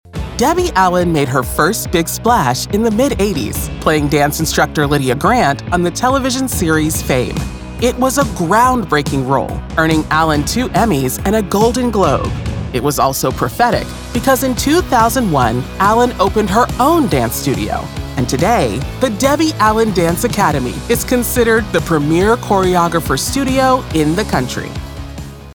Adult (30-50) | Yng Adult (18-29)